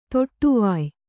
♪ toṭṭuvāy